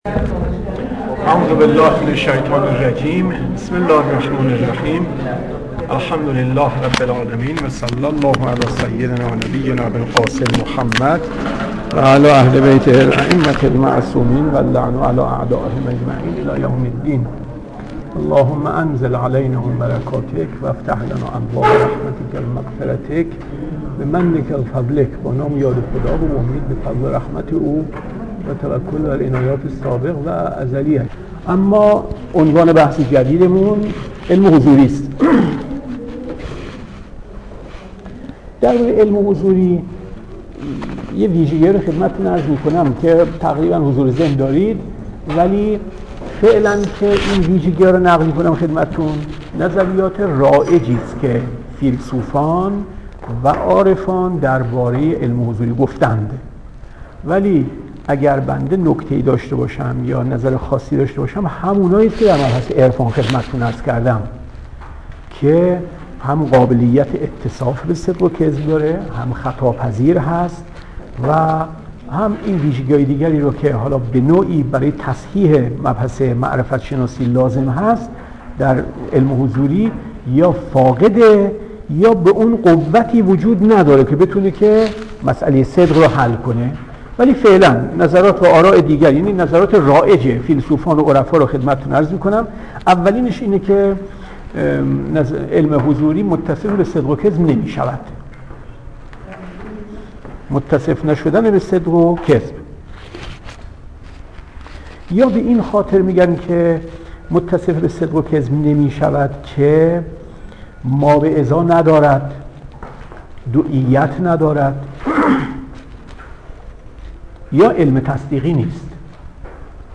درس صوتی فلسفه صدرالمتألهین2